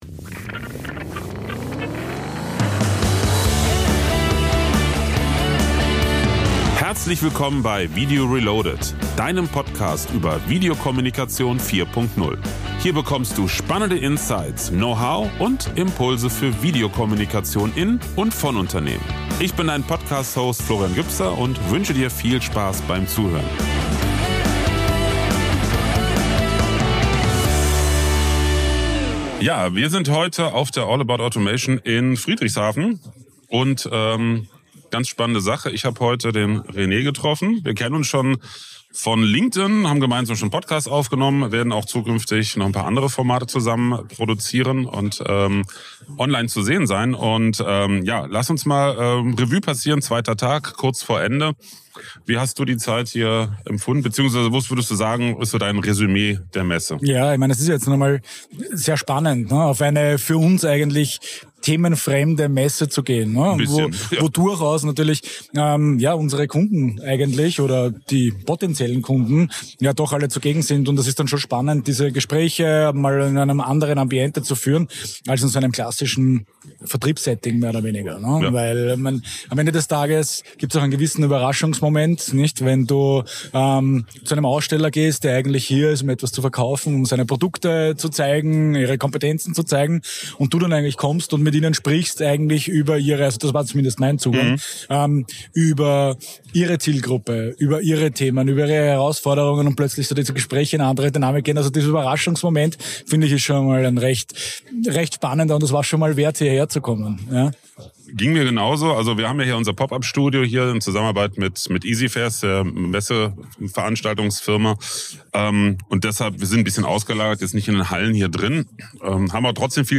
In dieser Episode nehme ich Dich mit auf die Messe All About Automation in Friedrichshafen.